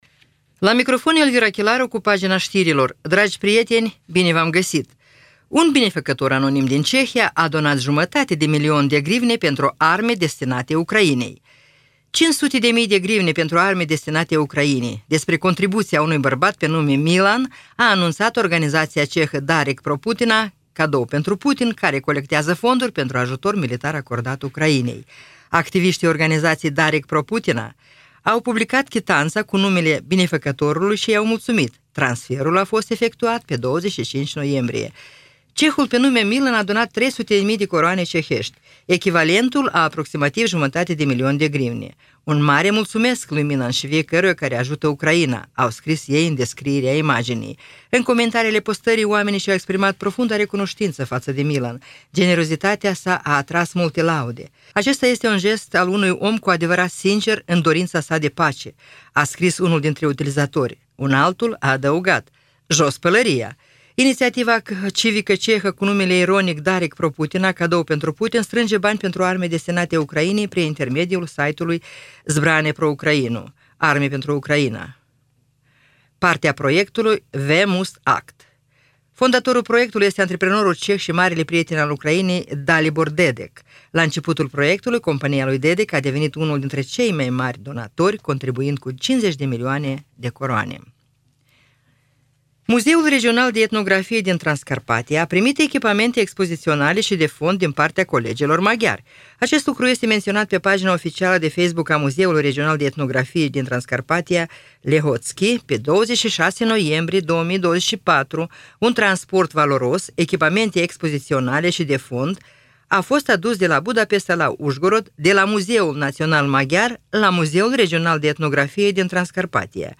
Ştiri Radio Ujgorod.